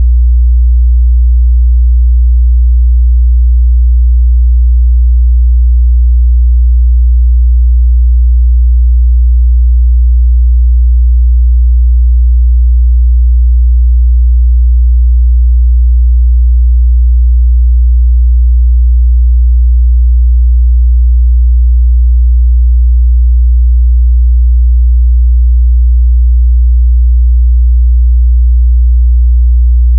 tone.wav